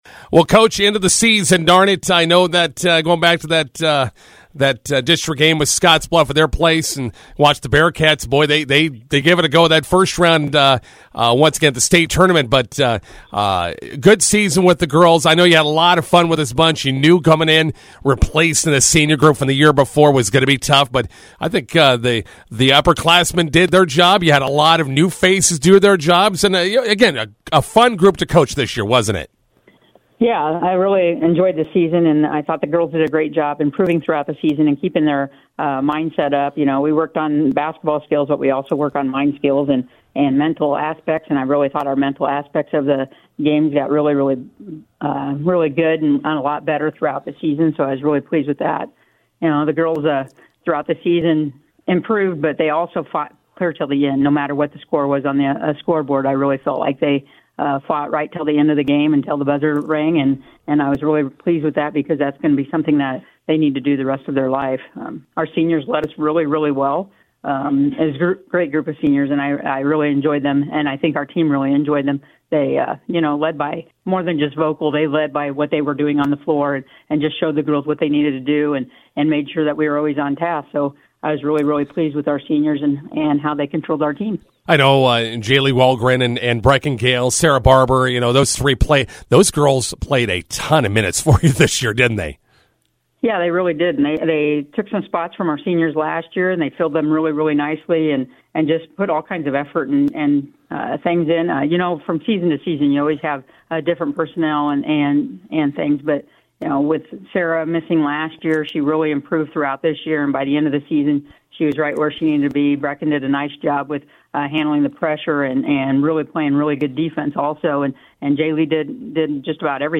INTERVIEW: Bison girls wrap up 25-26 basketball season.